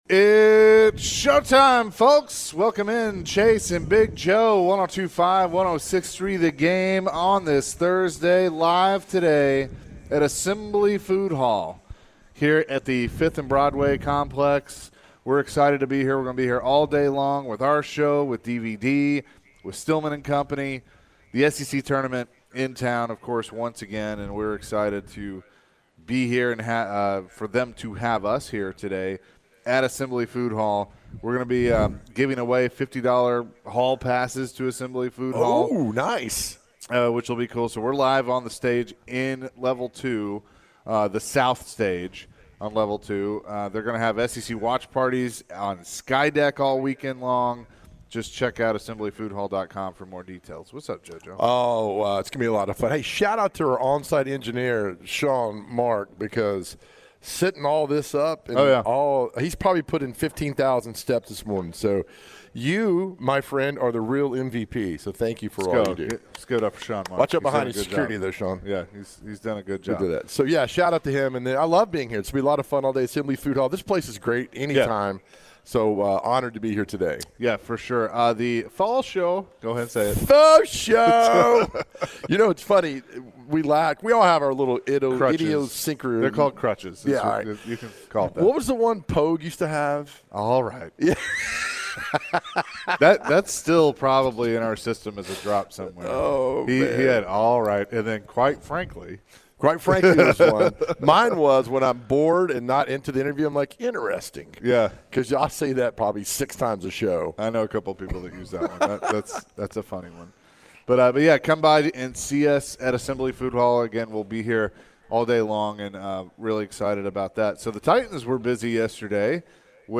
To end the hour, the guys answered some calls and texts surrounding the Titans.